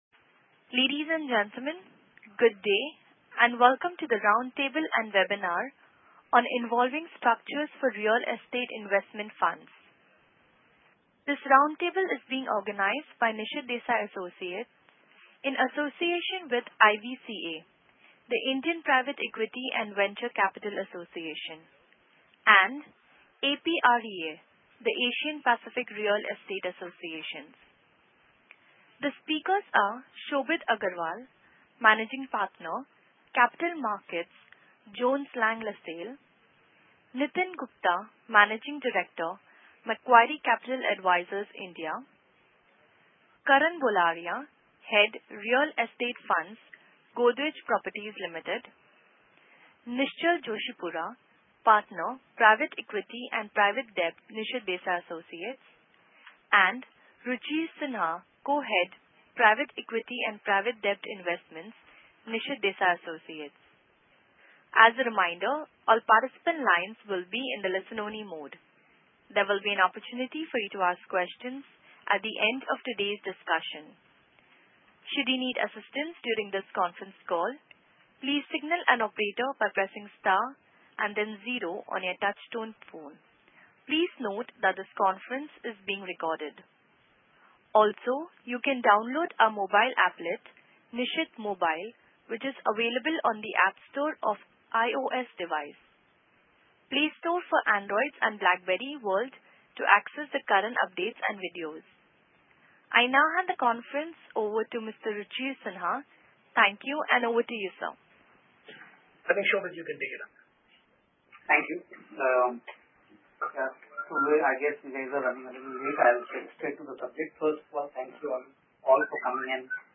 Round Table + Webinar: Evolving Structures for Real Estate Investment Funds (November 18, 2014)